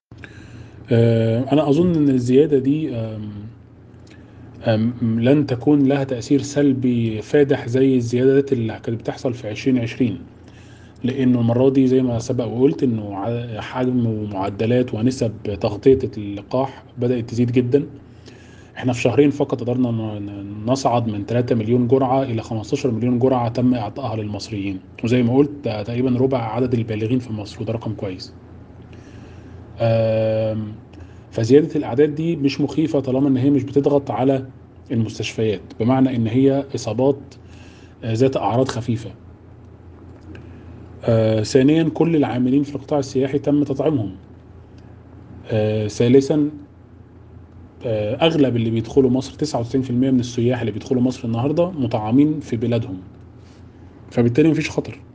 حوار
محلل اقتصادي